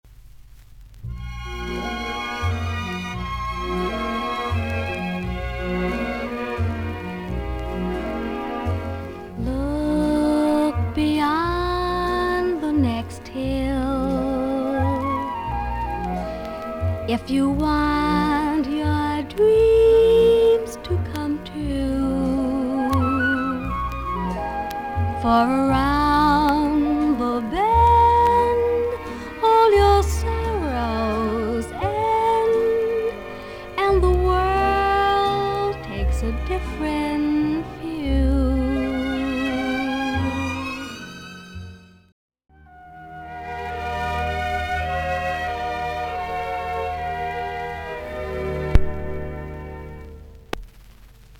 ほかはVG++〜VG+:少々軽いパチノイズの箇所あり。少々サーフィス・ノイズあり。クリアな音です。
キュート・ヴォイスでしっかりとジャズのセンスを持った希少な女性シンガー。
しっとりした落ち着いた雰囲気のアルバムです。